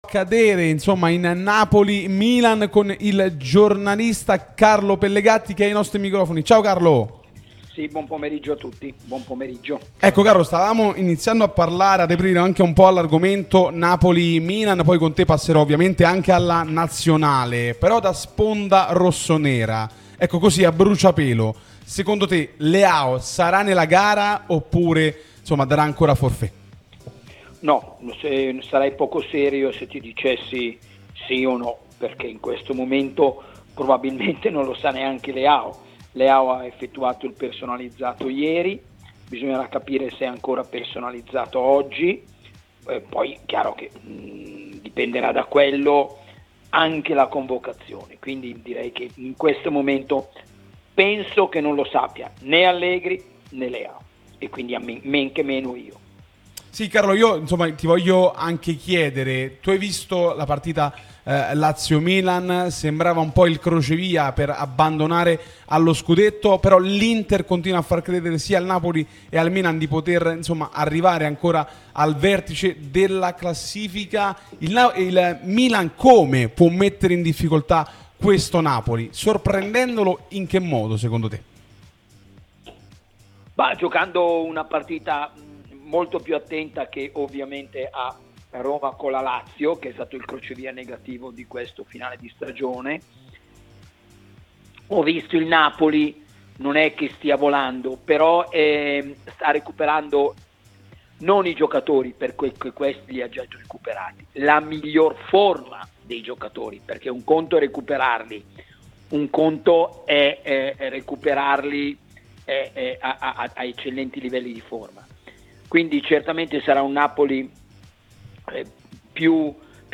Carlo Pellegatti, giornalista di fede milanista, è stato nostro ospite su Radio Tutto Napoli, l'unica radio tutta azzurra e sempre live, che puoi seguire sulle app gratuite (per Iphone o per Android, Android Tv ed LG), in auto col DAB o qui sul sito anche in video: "Se Leao giocherà o no?